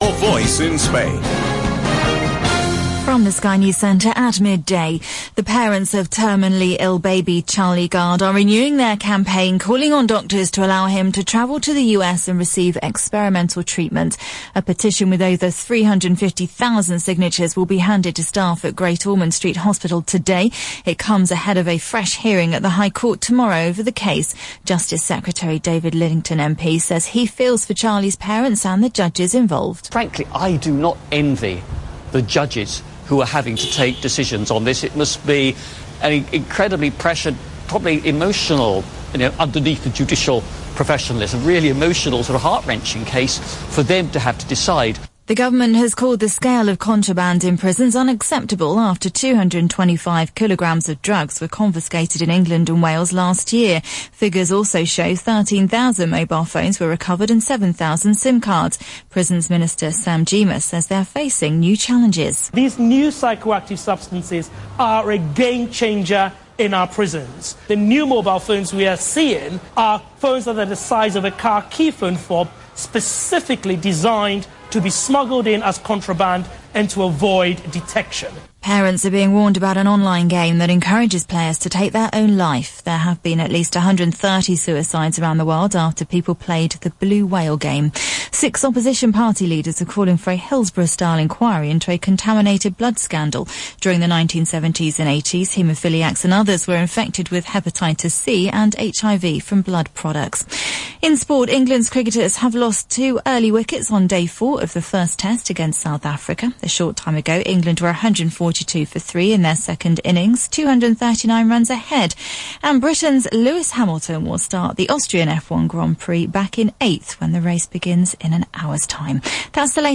I was on the radio the other day, a local version of ‘This is your Life.’